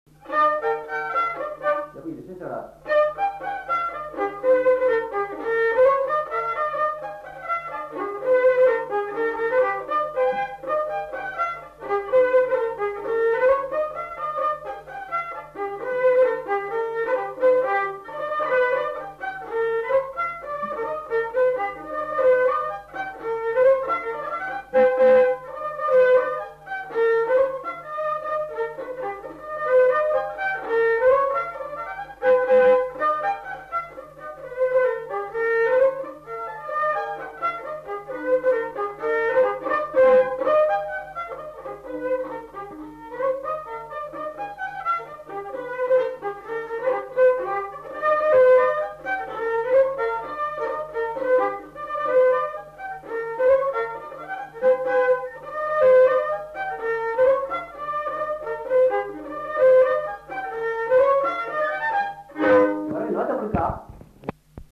Lieu : Casteljaloux
Genre : morceau instrumental
Instrument de musique : violon
Danse : polka